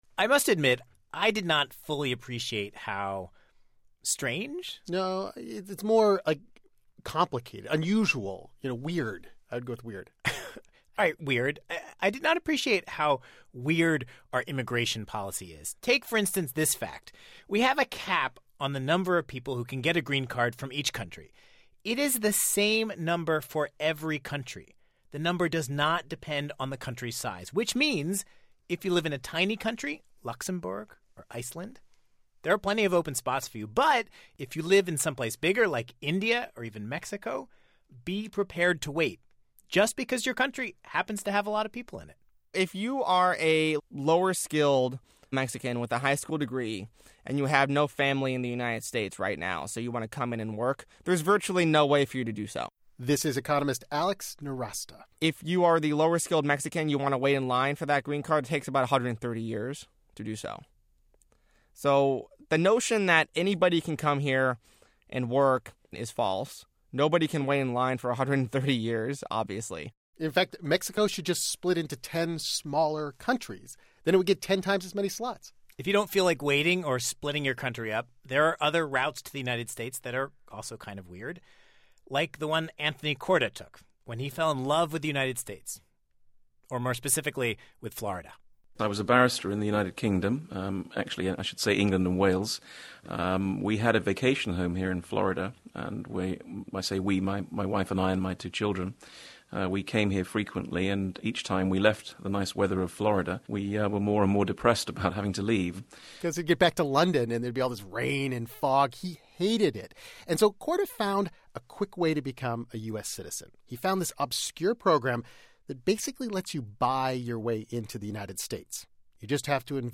For the first time in a while, there's political momentum building to change the U.S. immigration system. On today's show, we ask three economists: What would the perfect system look like?